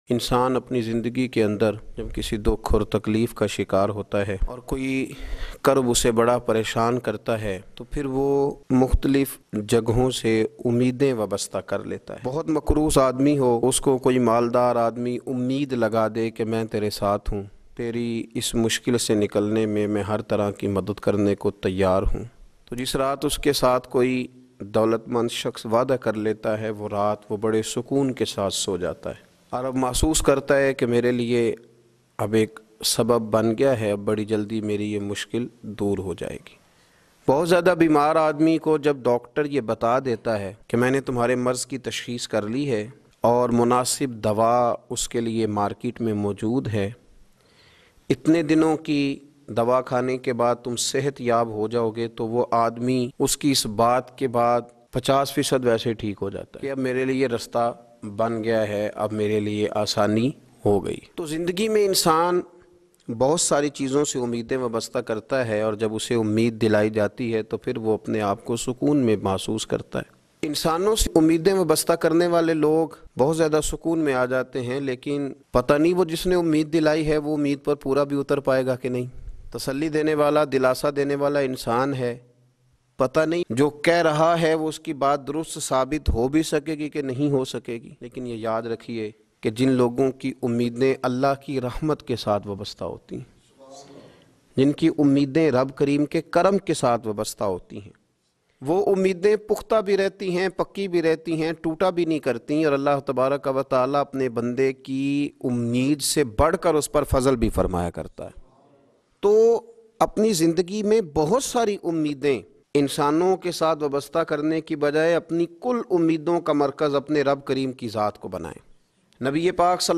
Hazrat Akasha Kis Baat Mein Sabqat Lay Gay Bayan MP3